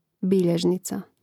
Rastavljanje na slogove: bi-ljež-ni-ca